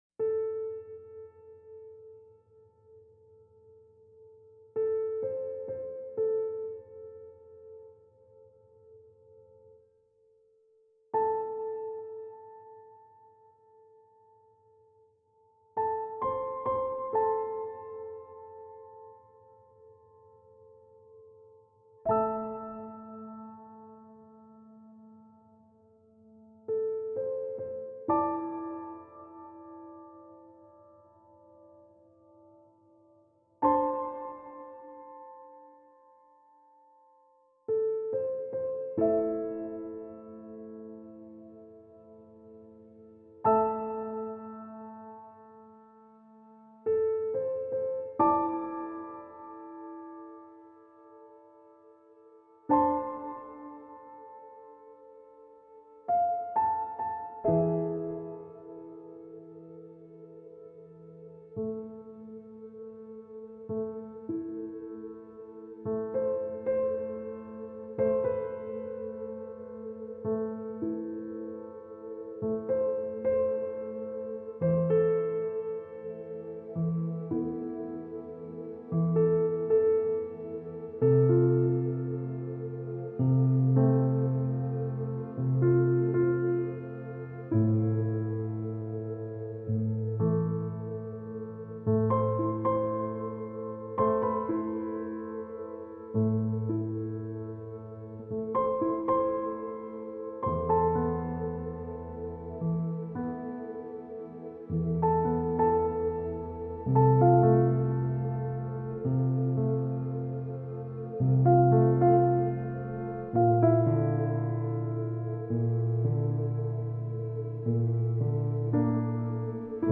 Orchestra material